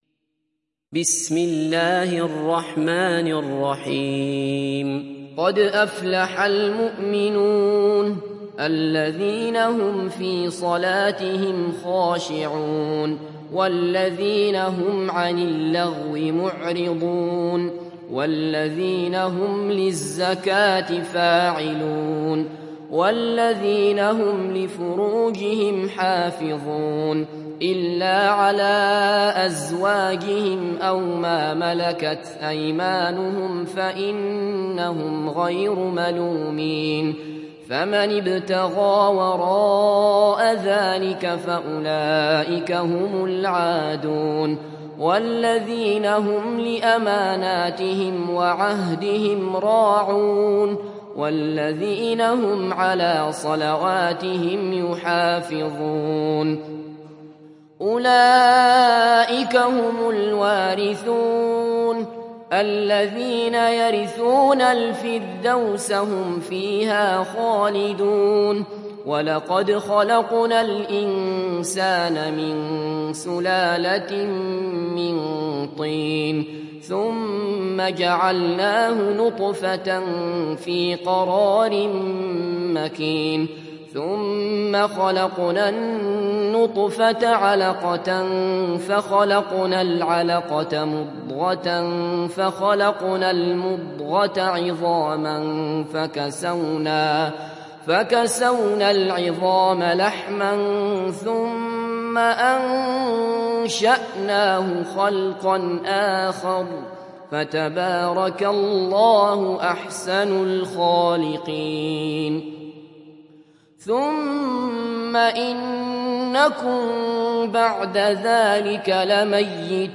تحميل سورة المؤمنون mp3 بصوت عبد الله بصفر برواية حفص عن عاصم, تحميل استماع القرآن الكريم على الجوال mp3 كاملا بروابط مباشرة وسريعة